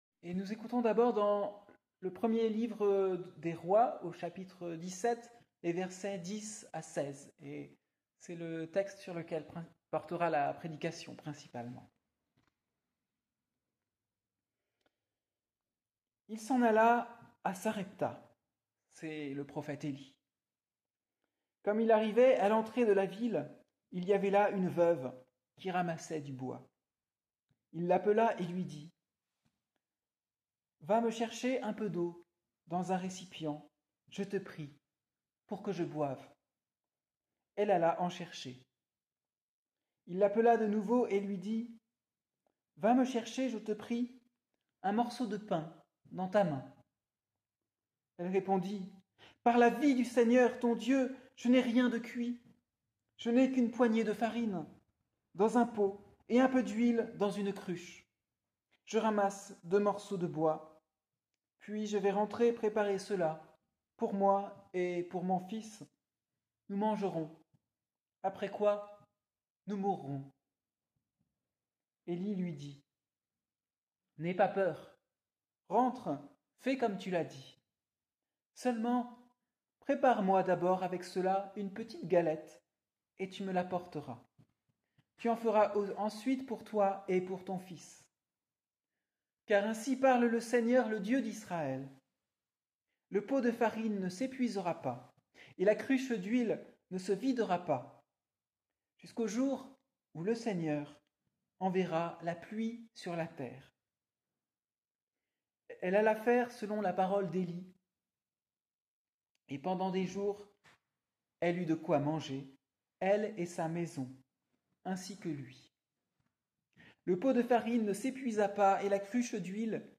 1. Textes bibliques